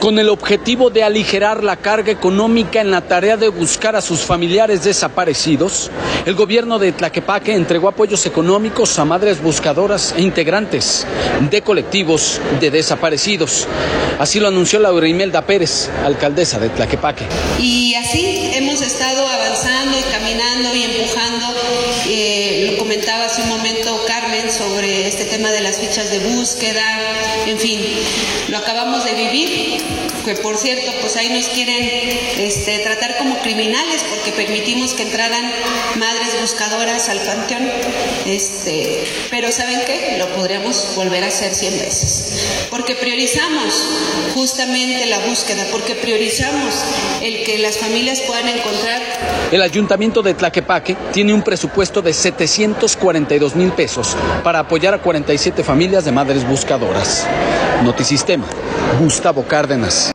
Con el objetivo de aligerar la carga económica en la tarea de buscar a sus familiares desaparecidos, el gobierno de Tlaquepaque entregó apoyos económicos a madres buscadoras e integrantes de colectivos de desaparecidos. Así lo anunció Laura Imelda Pérez, alcaldesa de Tlaquepaque.